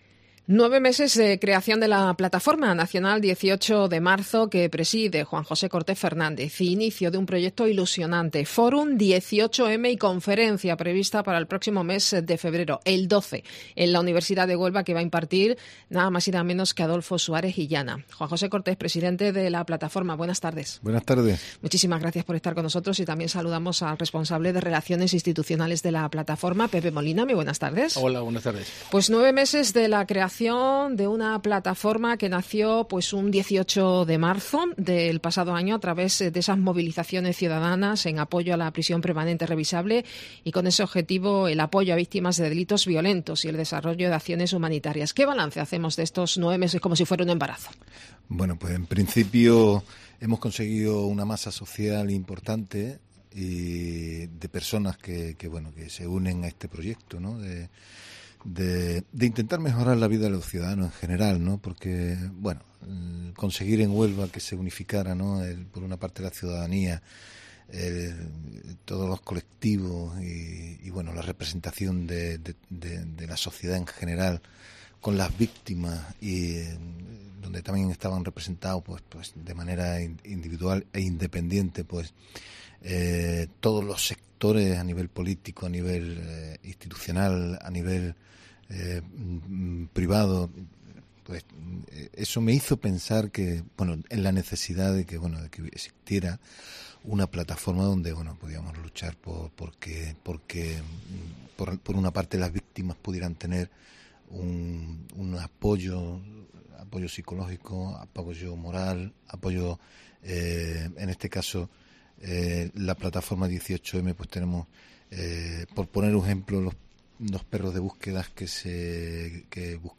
AUDIO: Conferencia de Adolfo Suárez Illana en Huelva